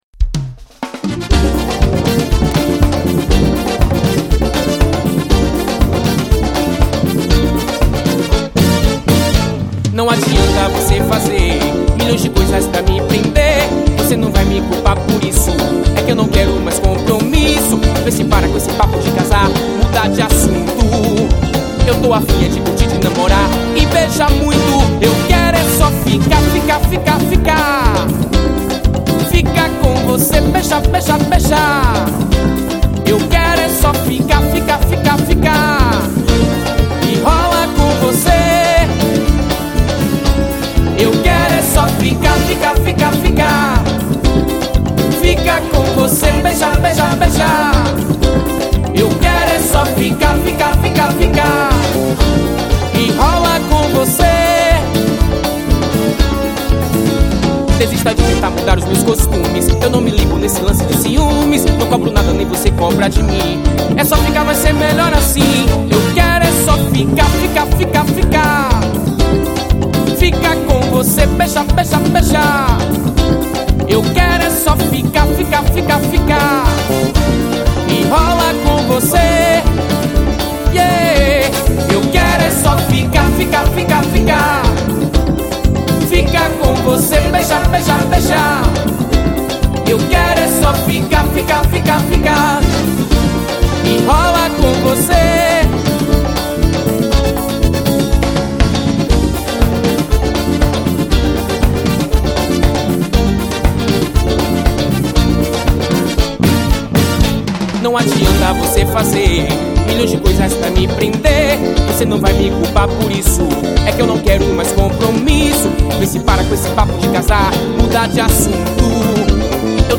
EstiloAxé